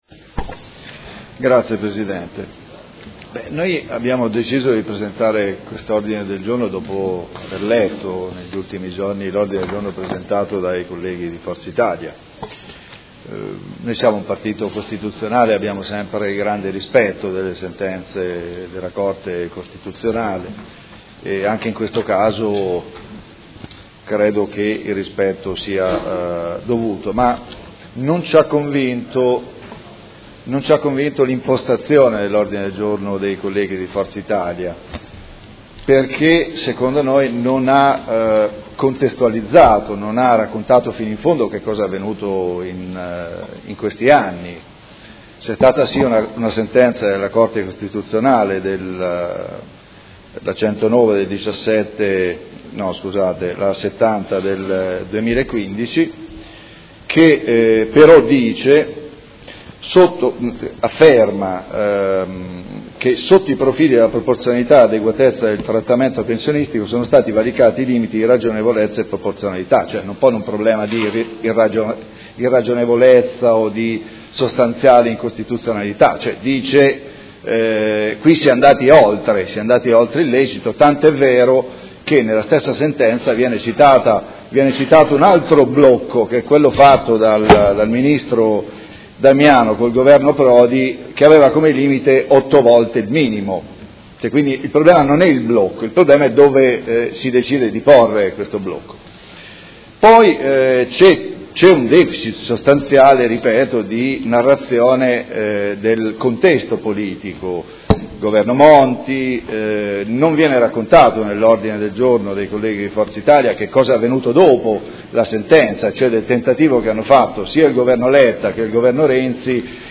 Paolo Trande — Sito Audio Consiglio Comunale
Seduta del 28 gennaio. Ordine del Giorno n° 12761 presentato dal Gruppo Consiliare del PD avente per oggetto: Rivalutare e riformare il sistema pensionistico....